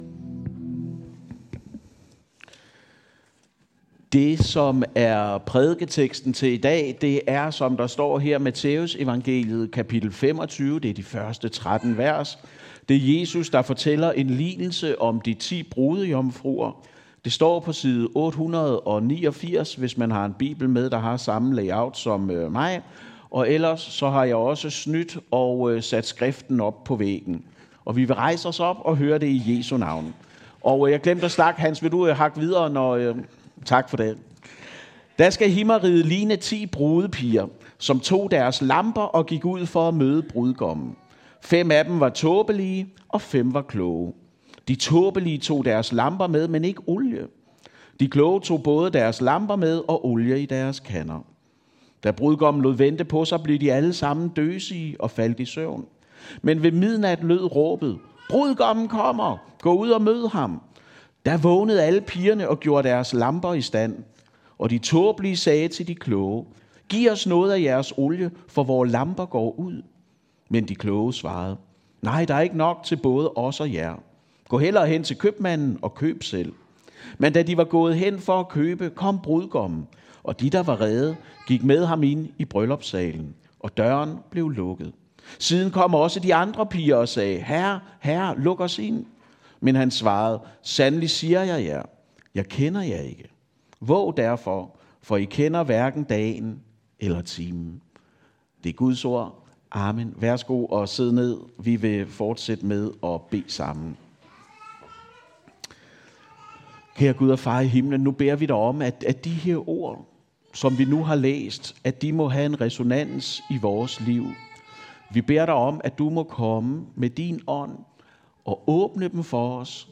Lyt til prædikener optaget i Kirken Ved Søerne